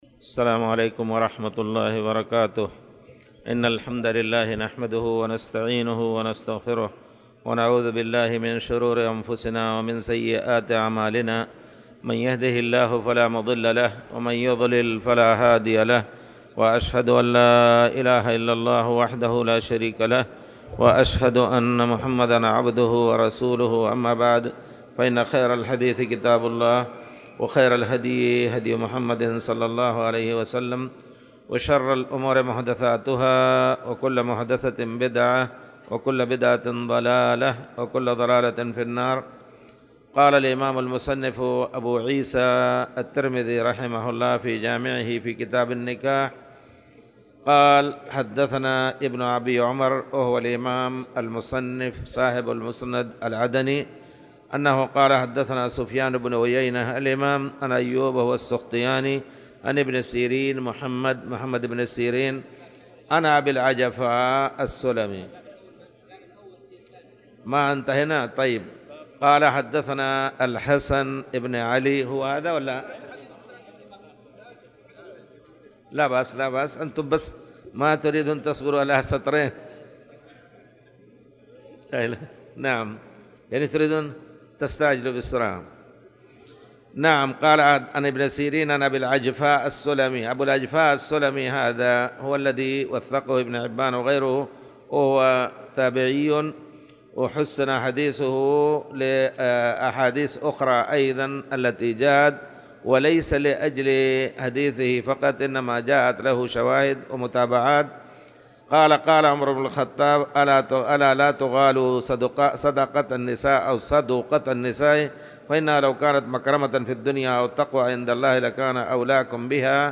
تاريخ النشر ٤ محرم ١٤٣٨ هـ المكان: المسجد الحرام الشيخ